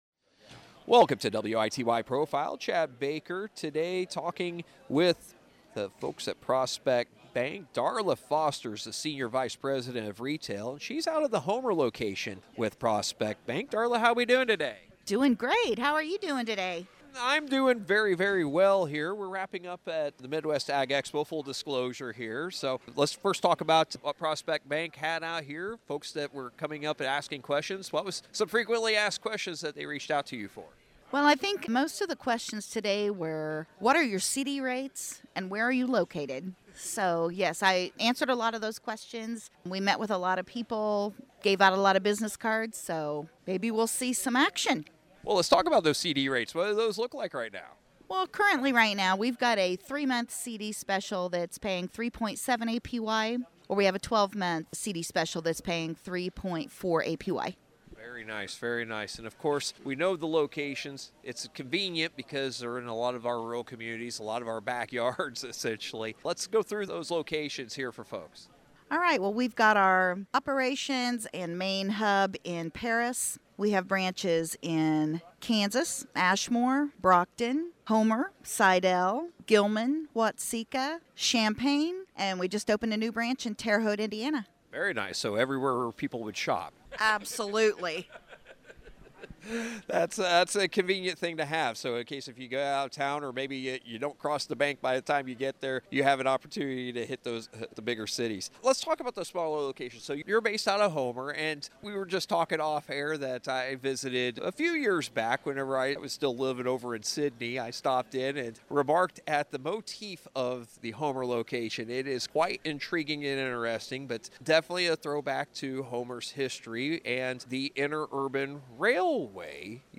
at the Midwest Ag Expo